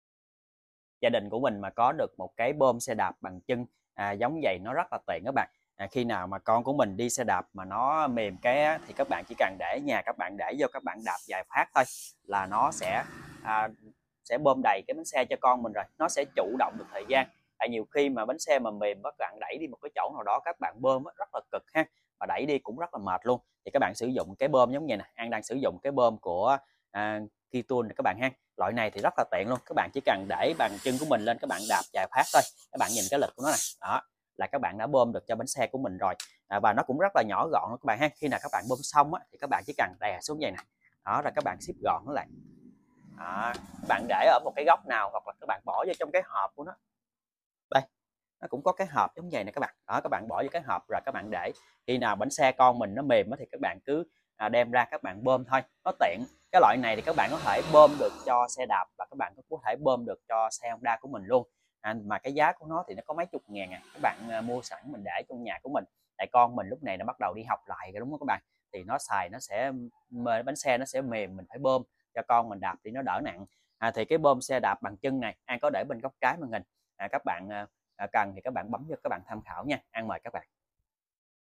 Ống bơm đạp chân xe sound effects free download